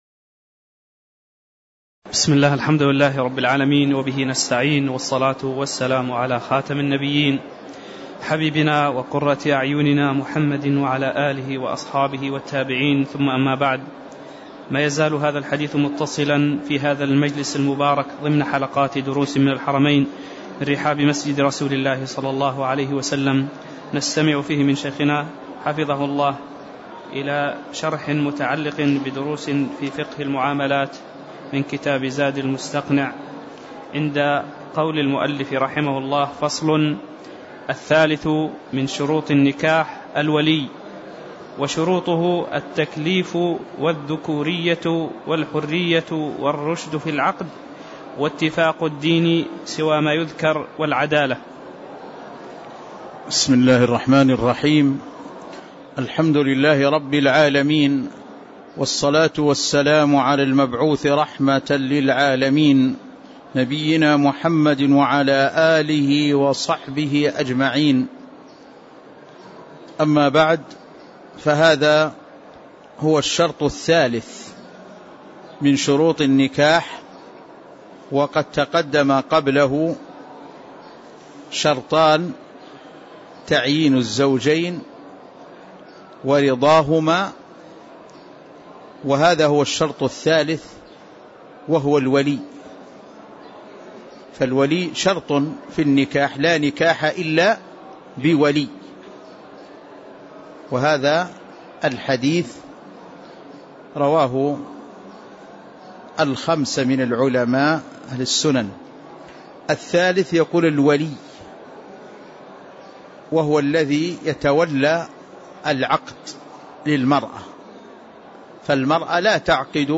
تاريخ النشر ٣٠ ربيع الثاني ١٤٣٧ هـ المكان: المسجد النبوي الشيخ